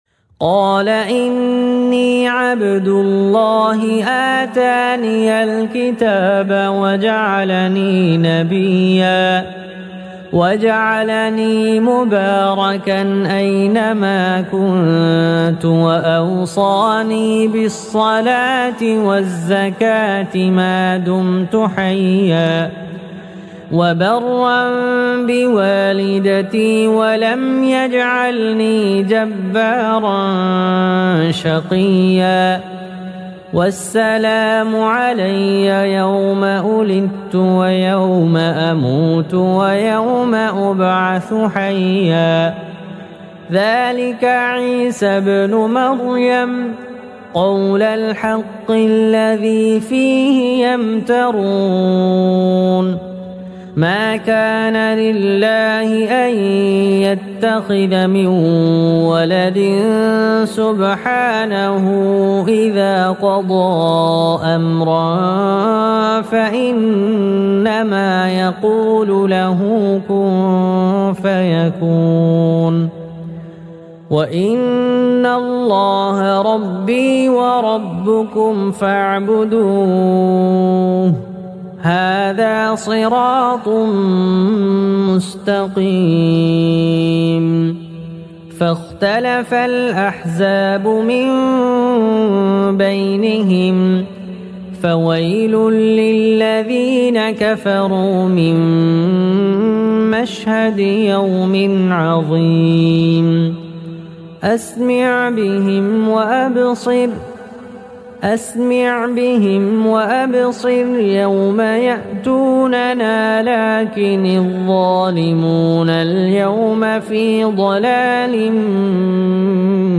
Recitation Of Quran Surah Maryam